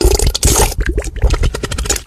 Sound Effects
Wet-fartsss